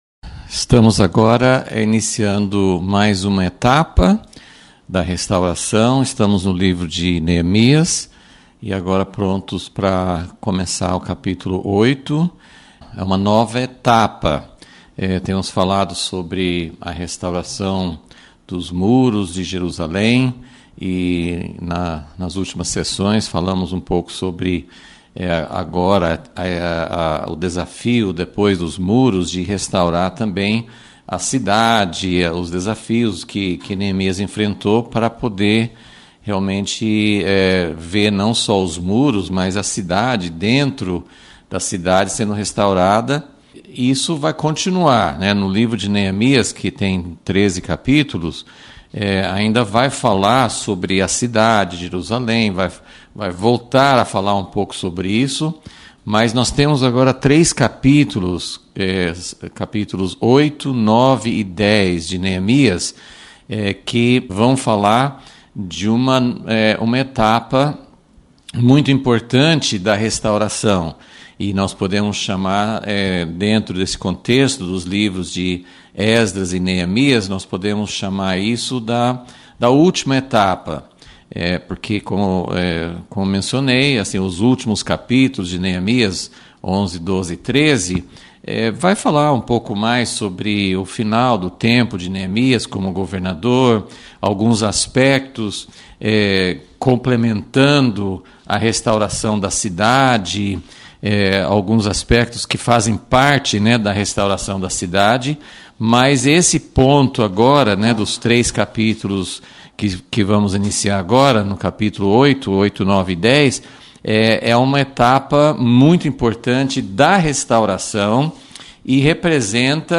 Aula 19 – Vol.36 – O Último Estágio da Restauração